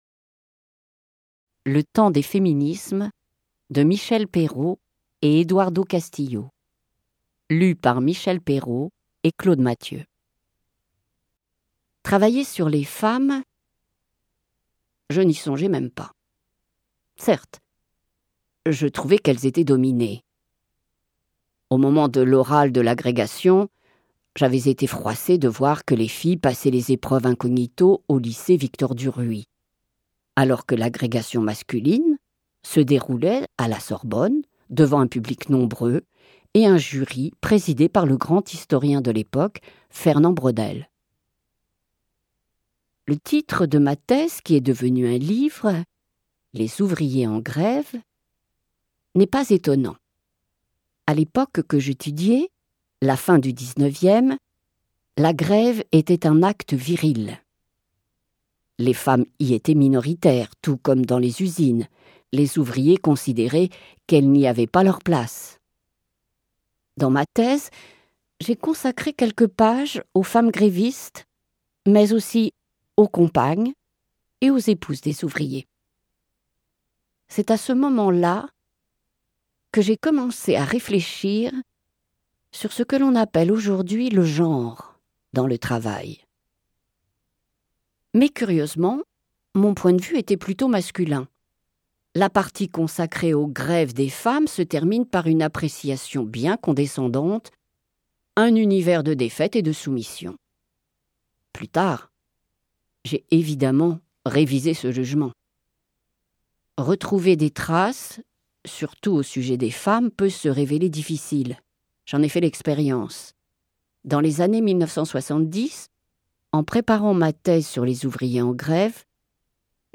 Diffusion distribution ebook et livre audio - Catalogue livres numériques
En compagnie de Claude Mathieu de la Comédie-Française, Michelle Perrot prête sa voix à cet ouvrage marquant.«